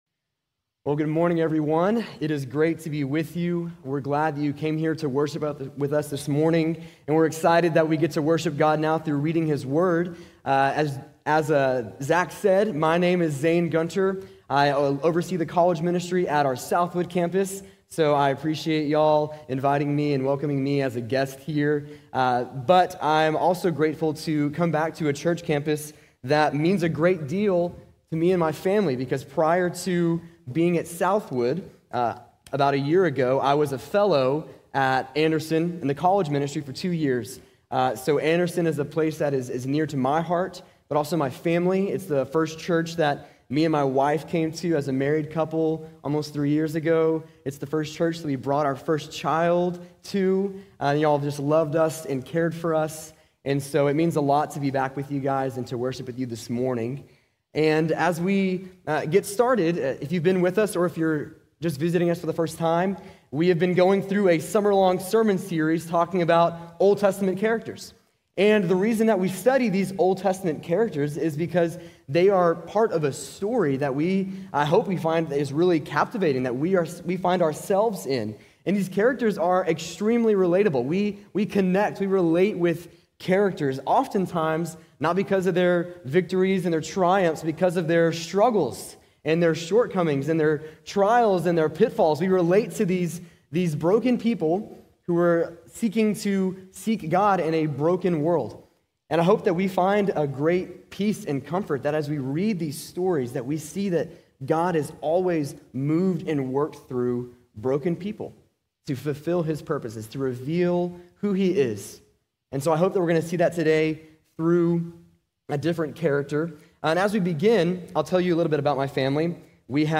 How to Handle Hard Times | Sermon | Grace Bible Church